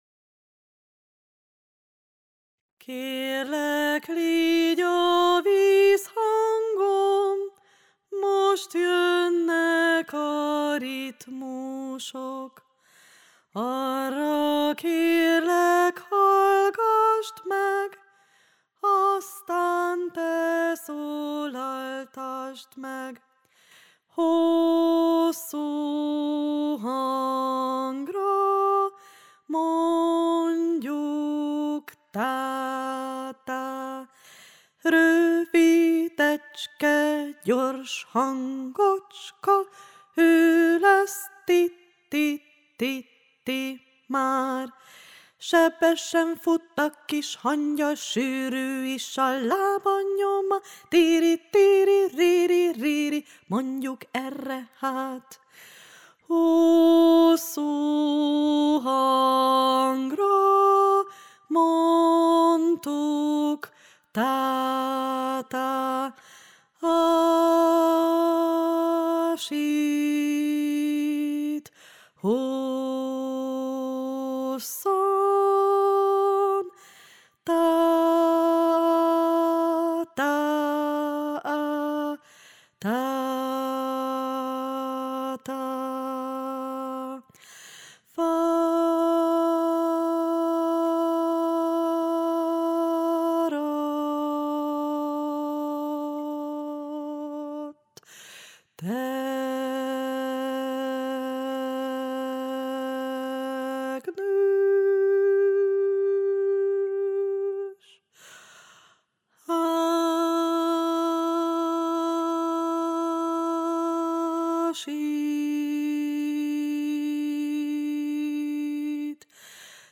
RITMUSVILÁG mondóka _ Köszöntő _ újonnan érkezők előképzős és első zenei osztályosok számára